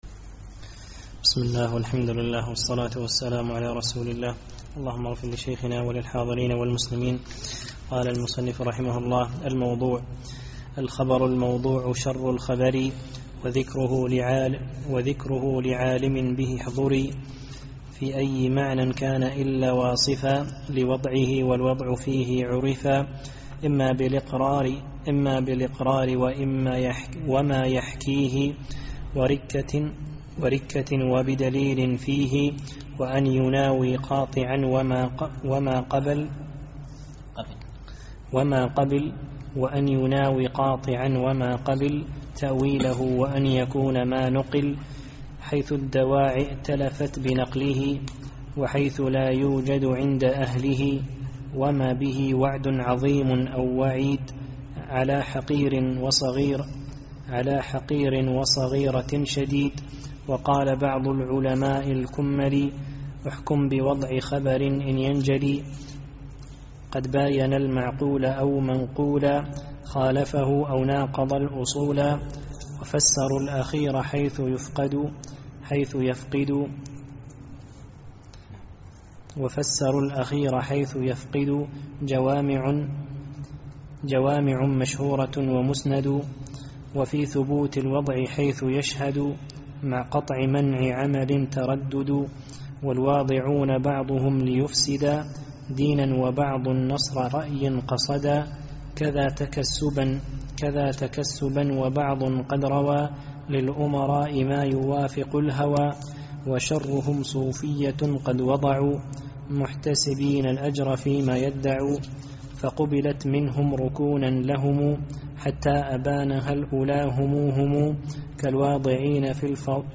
الدرس الثالث عشر